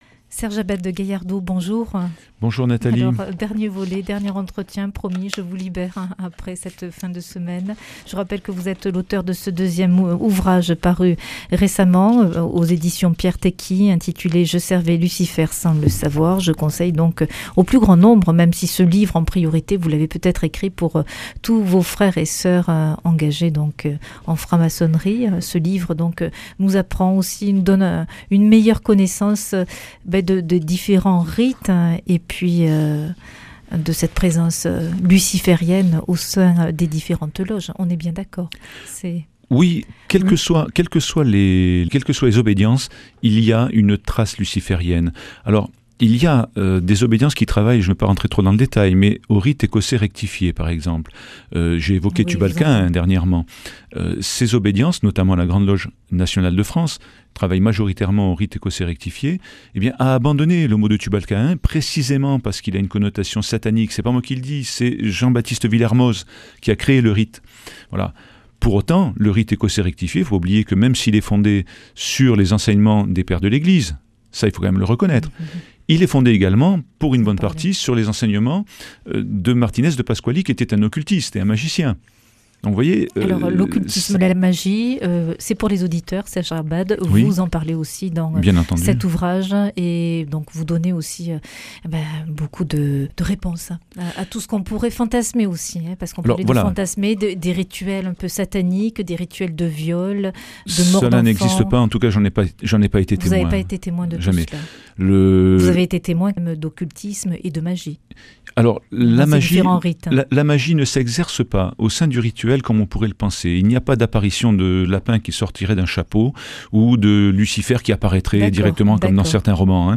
Des clés pour vivre : Entretien 5 Pourquoi la franc-maçonnerie fait référence à la bible ?